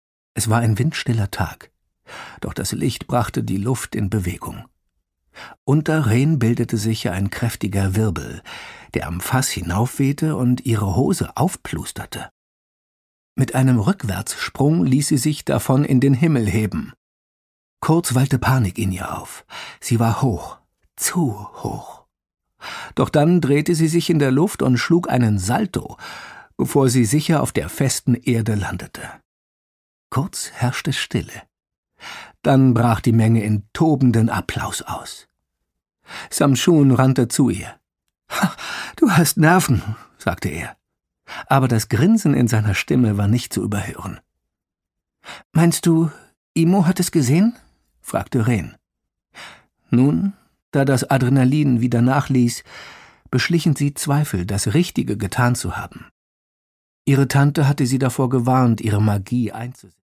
Produkttyp: Hörbuch-Download
fesselt mit seiner markanten Stimme von der ersten bis zur letzten Minute